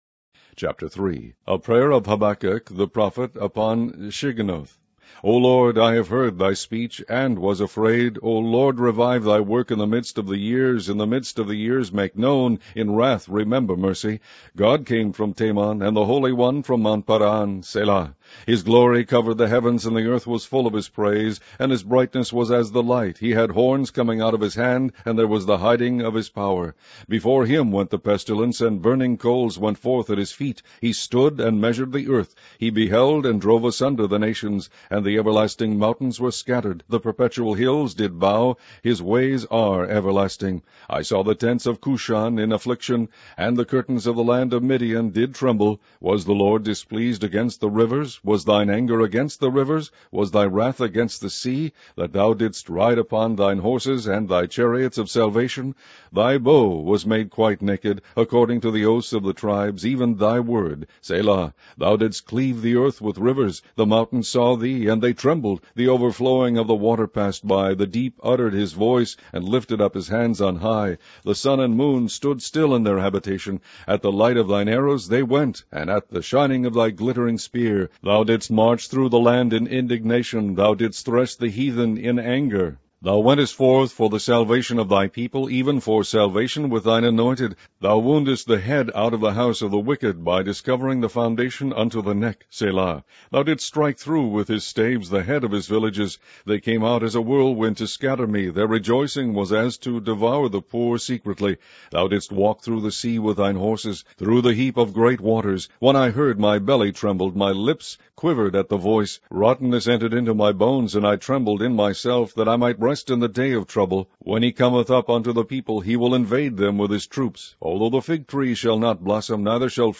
Online Audio Bible - King James Version - Habakkuk